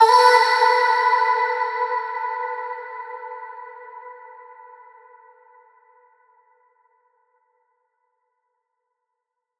VR_vox_hit_ah_Emin.wav